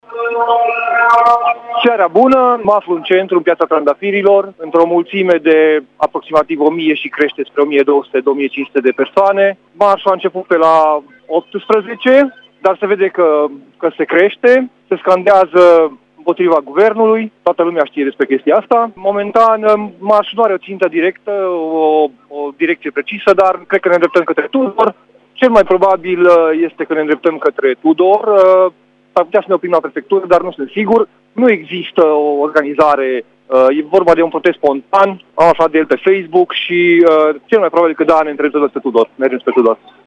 Protestatar târgumureșean